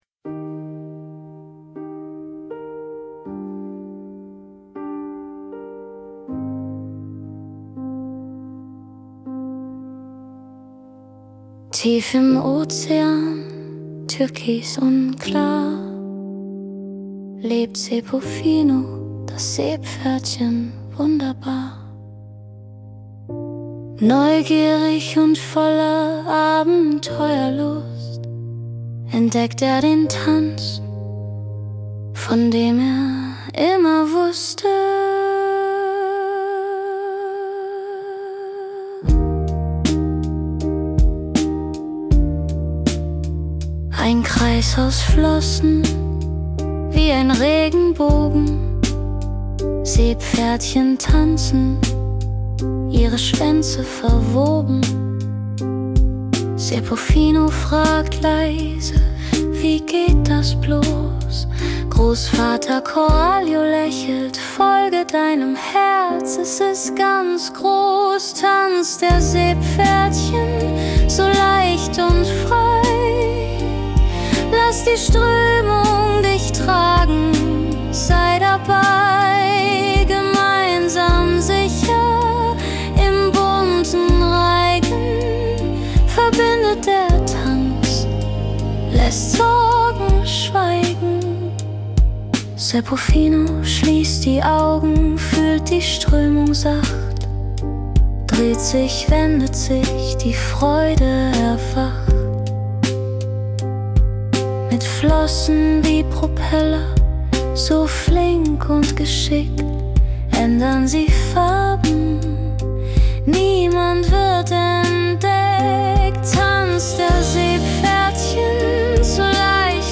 Das Schlaflied zur Geschichte